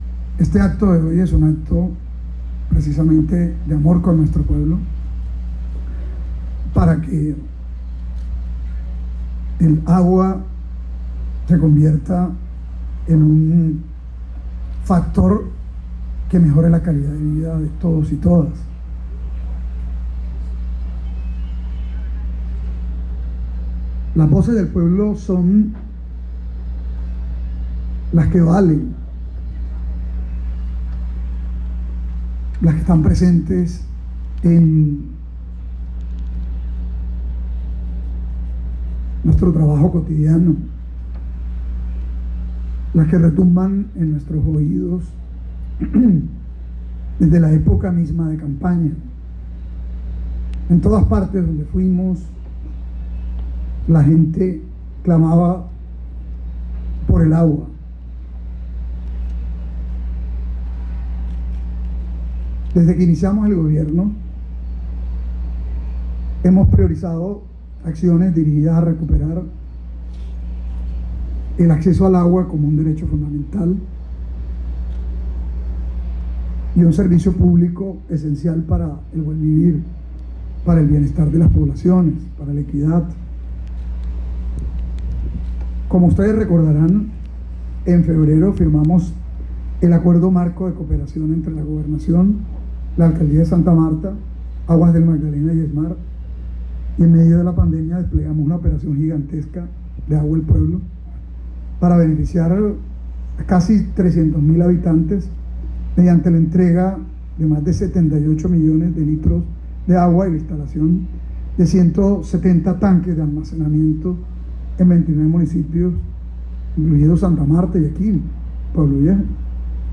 El anuncio lo hizo el propio gobernador en el Parador Turístico de Puebloviejo frente a 32 miembros de los comités populares de obra, quienes fueron en representación de los 14 municipios que se beneficiarán con estos proyectos que buscan fortalecer la infraestructura de acueducto y alcantarillado, para dignificar la vida de estas poblaciones.